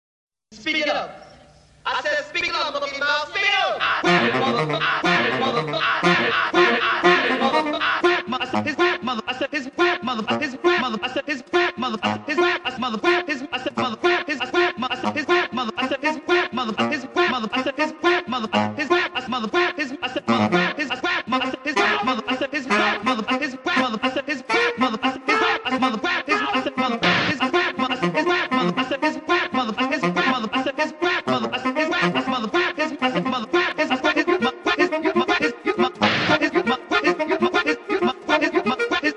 テナーサックスとゲットブラスター（テープ）のためのソロ作品